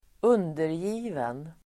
Ladda ner uttalet
undergiven.mp3